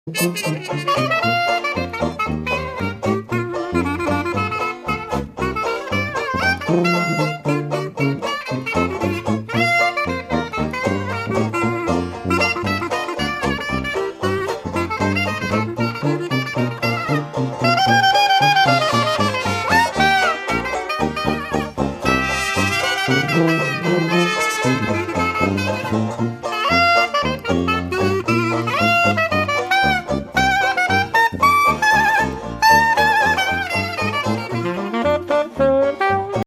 TRADITIONAL JAZZ
Traditioneller New Orleans Jazz . . .